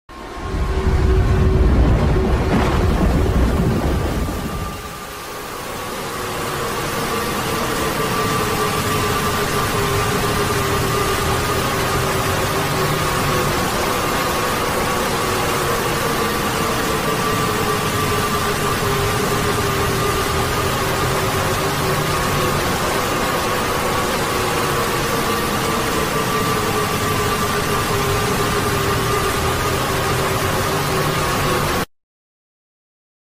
Roblox doors ambush arrival sound sound effects free download
Roblox doors ambush arrival sound effect